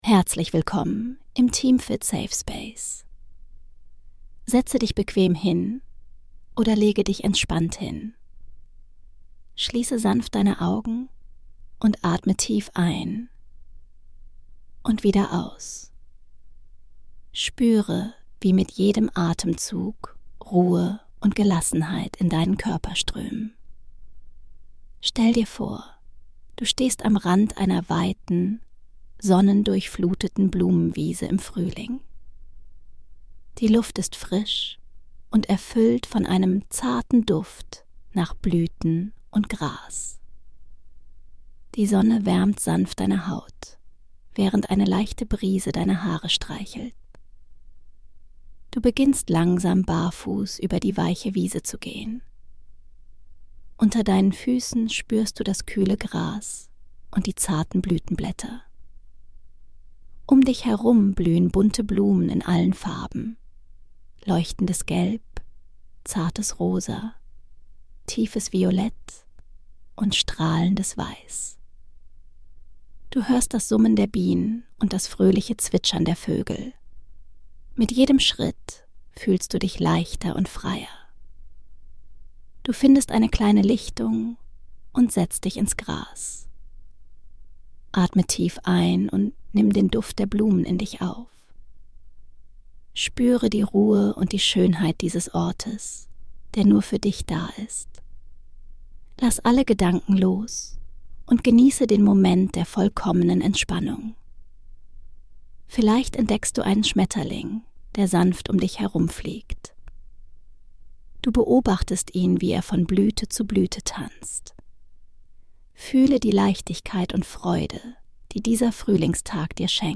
Entspanne dich bei einer geführten Traumreise über eine blühende